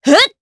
Lorraine-Vox_Attack1_jp.wav